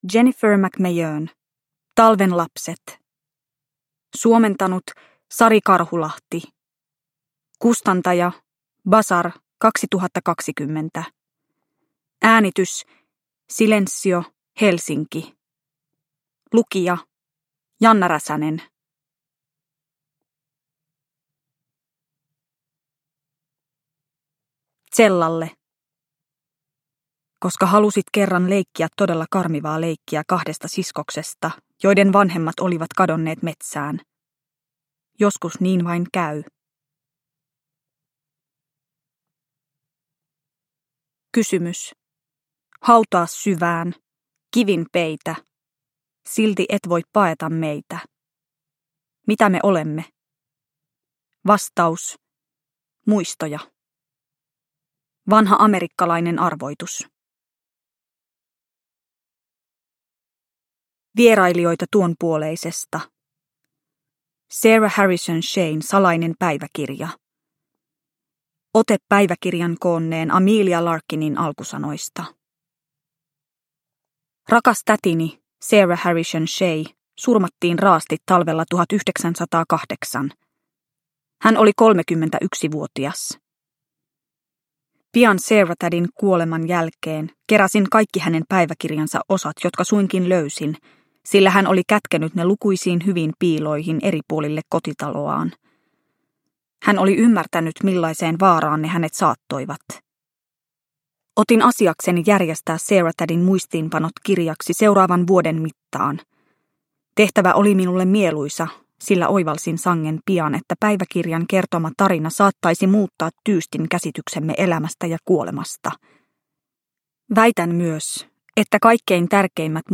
Talven lapset – Ljudbok – Laddas ner